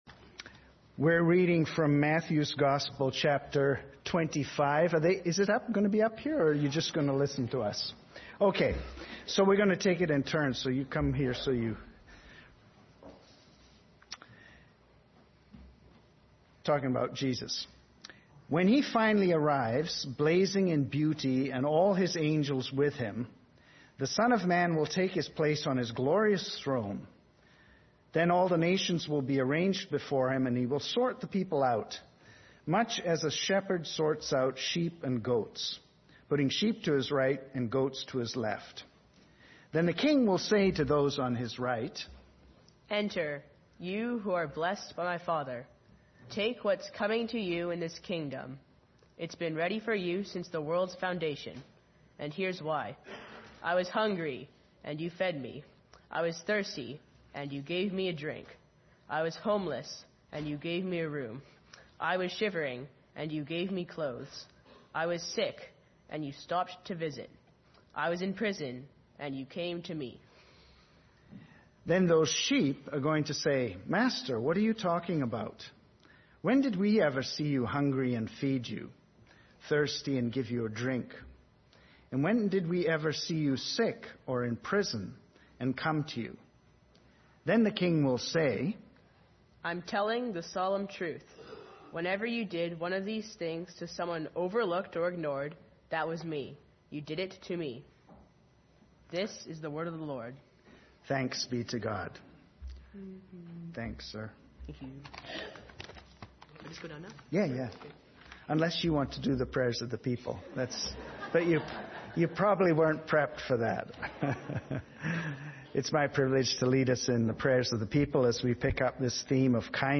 Sermons | Olivet Baptist Church
Family Service